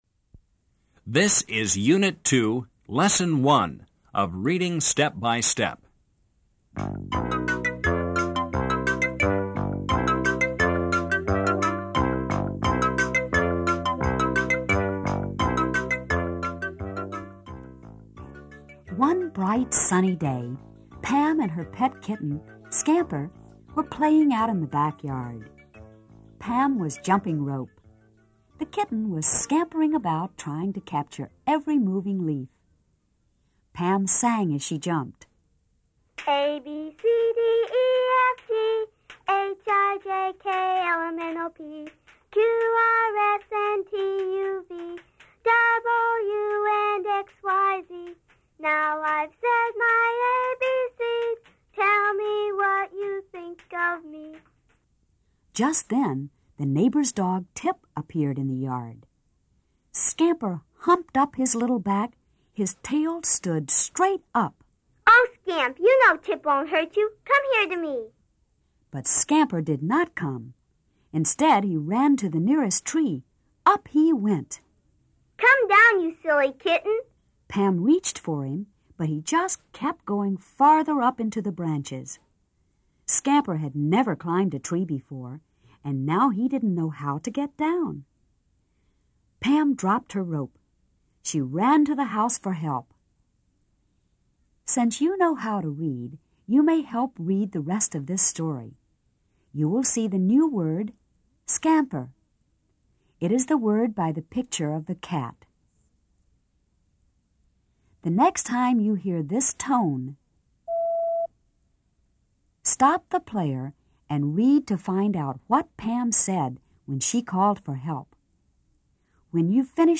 DIGITAL DOWNLOAD AUDIO DIRECTED LESSON WITH REPRODUCIBLE STUDENT ACTIVITY SHEETS AND AN AUDIO MP3 FILE
Appealing illustrations and large print are combined with dramatization, dialogue, music and sound effects.